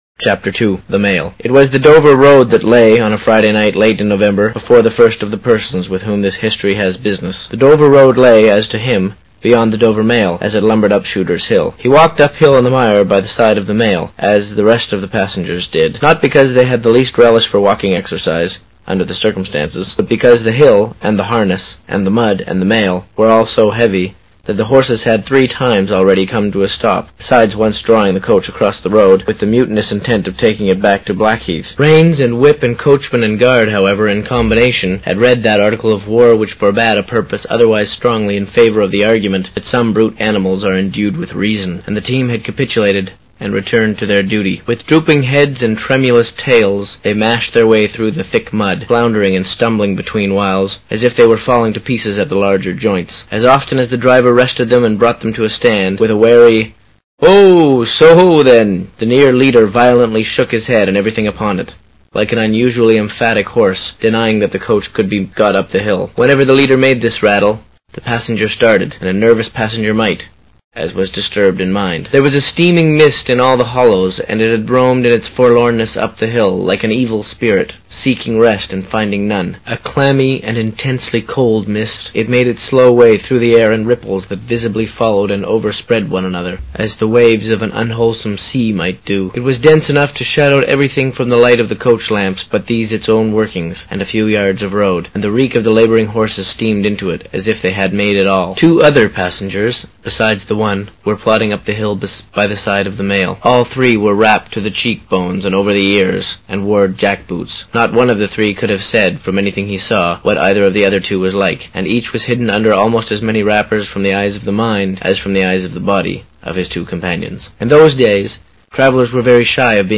有声名著之双城记Book1 Chapter02 听力文件下载—在线英语听力室